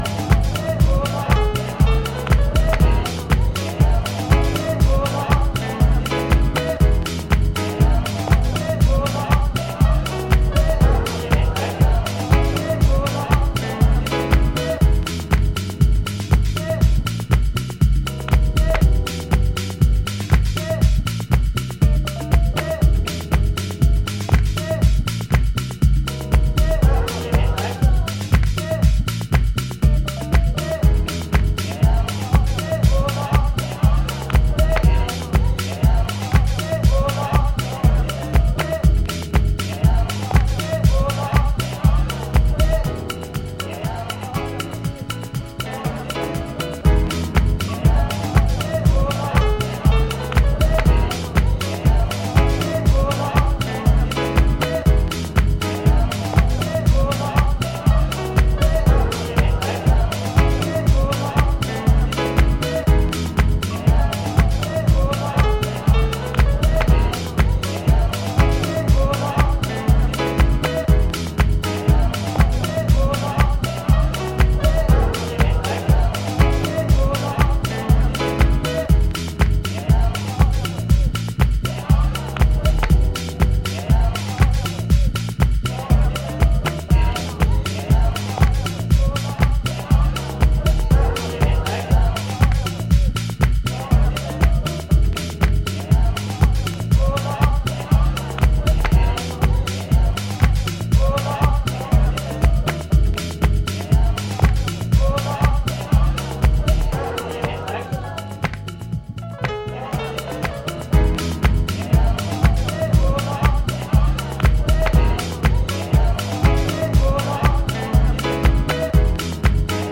the heavy edit funk
gold-standard Detroit house start to finish